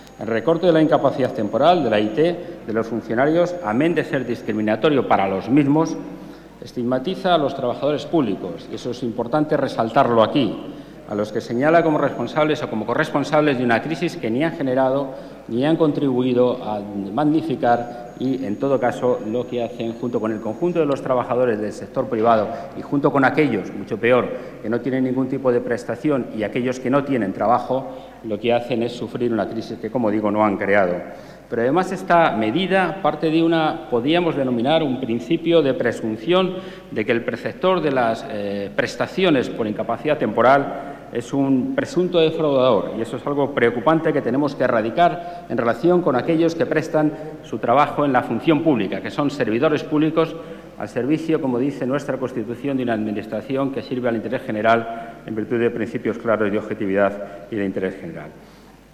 Pleno en el Congreso.